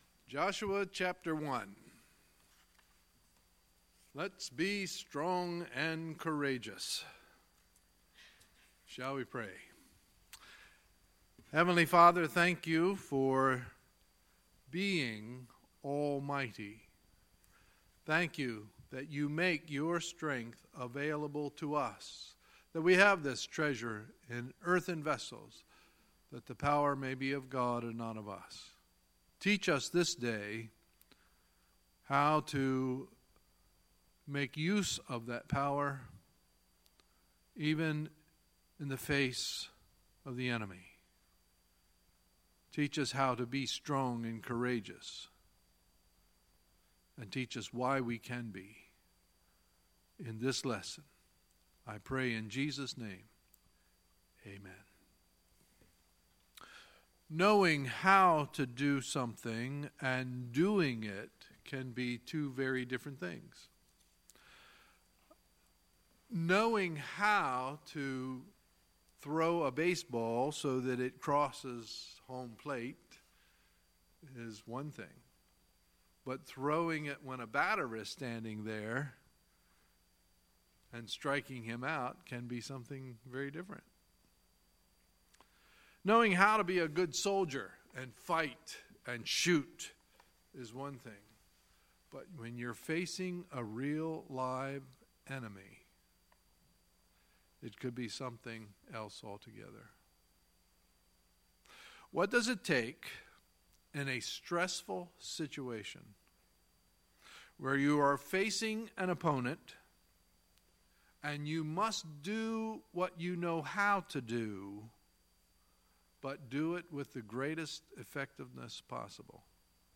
Sunday, October 22, 2017 – Sunday Morning Service